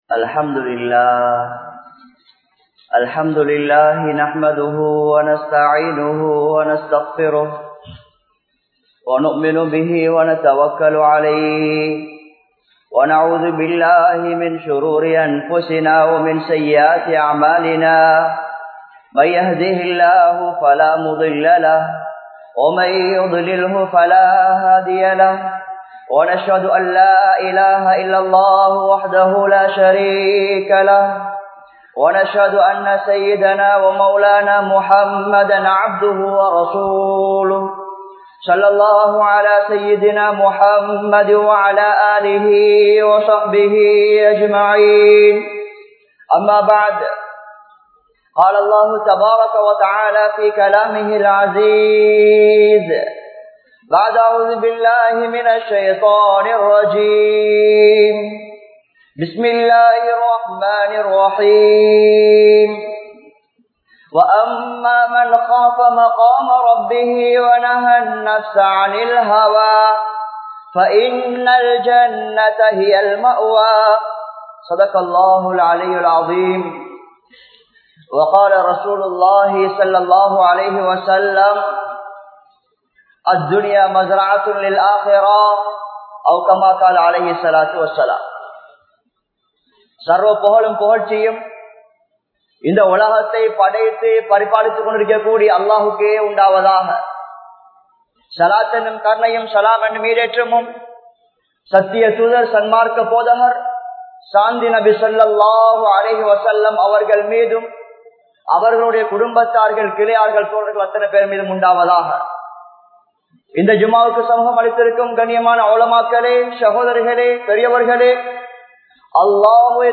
Alahana Kudumba Vaalkai Veanduma? (அழகான குடும்ப வாழ்க்கை வேண்டுமா?) | Audio Bayans | All Ceylon Muslim Youth Community | Addalaichenai
Al Kabeer Jumua Masjidh